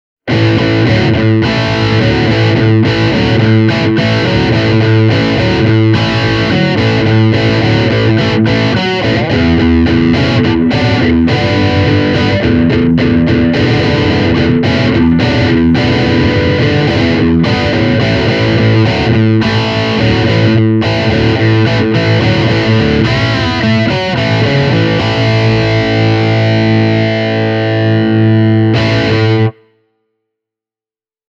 Tehtaalta on asennettu kolmoskanavaan R-fier Stack -malli – siis Rectifier-meininkiä (ensin Telecasterilla, sen jälkeen Melody Maker SG:llä soitettuna):
Cube-10GX kuulostaa yllättävän isolta myös bassovahvistimena (kaikki ääninäytteet äänitetty Shure SM57:lla):